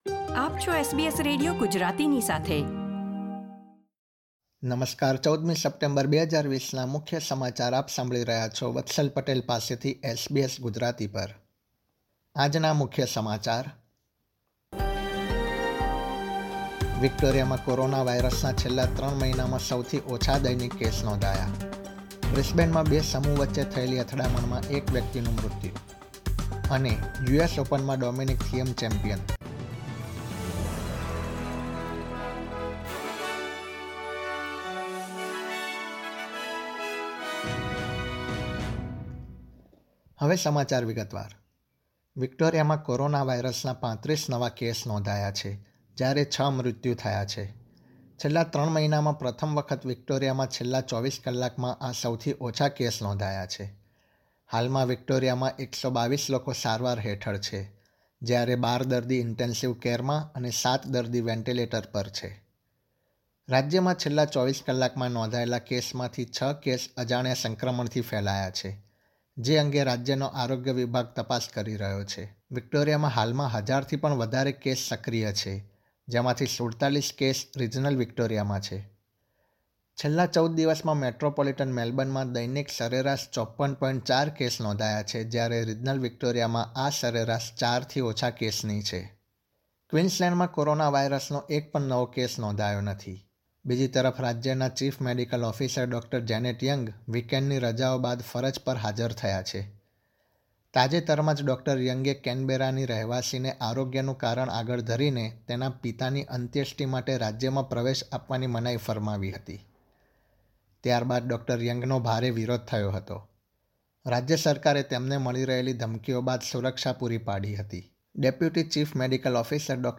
SBS Gujarati News Bulletin 14 September 2020
gujarati_1409_newsbulletin.mp3